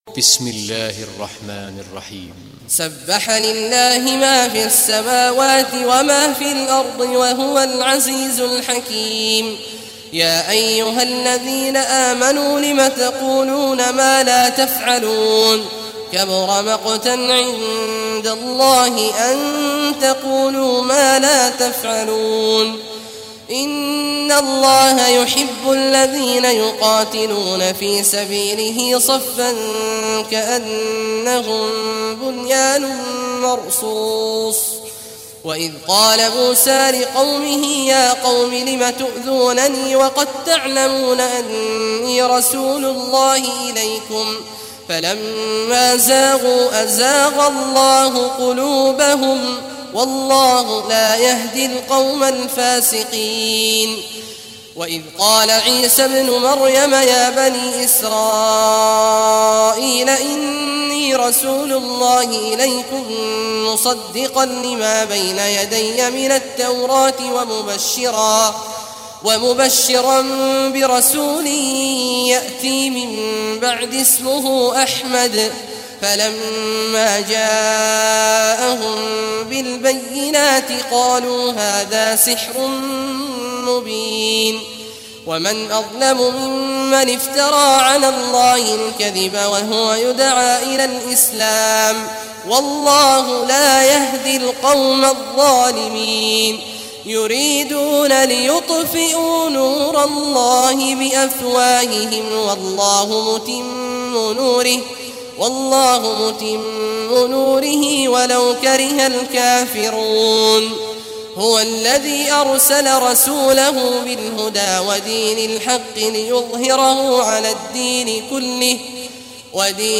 Surah As-Saff Recitation by Sheikh Awad Juhany
Surah As-Saff, listen or play online mp3 tilawat / recitation in Arabic in the beautiful voice of Sheikh Abdullah Awad al Juhany.